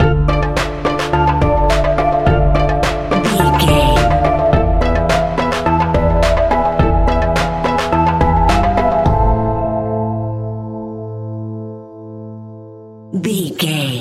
Aeolian/Minor
tension
ominous
dark
eerie
synthesiser
electric piano
strings
drums
percussion
horror music